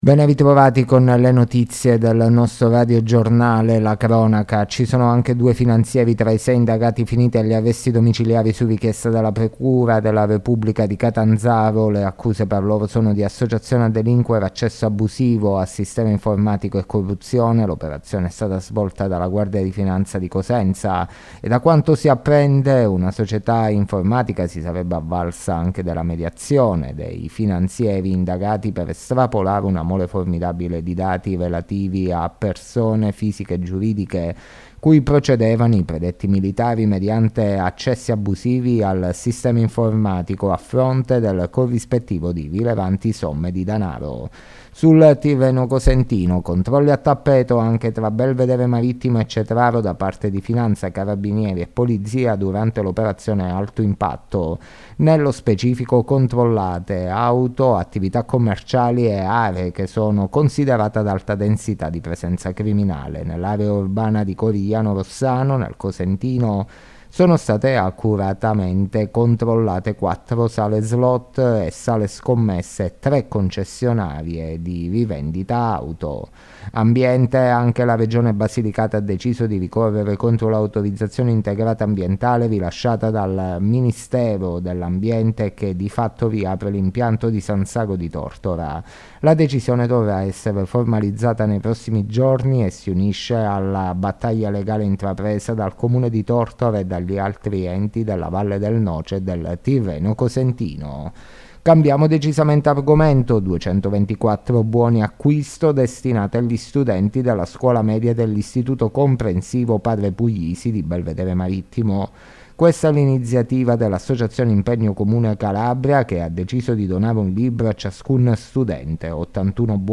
Le notizie della sera di Martedì 28 Novembre 2023